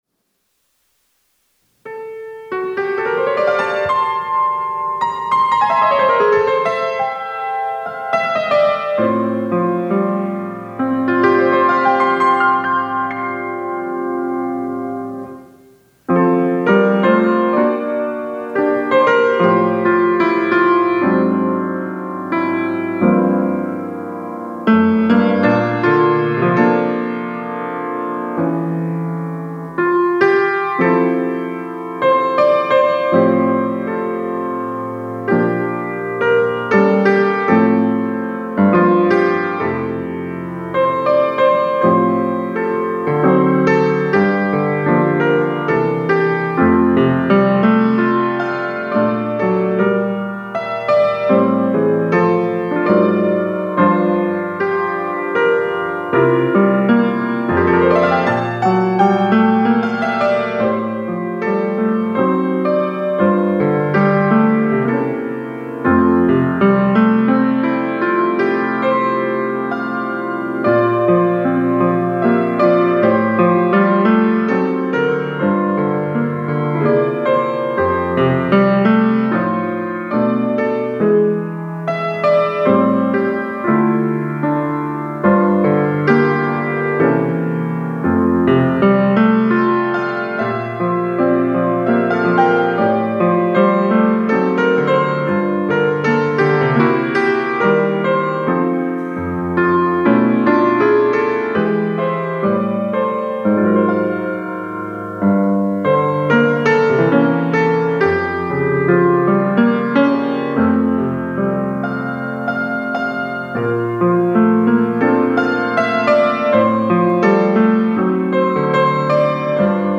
특송과 특주 - 그 크신 하나님의 사랑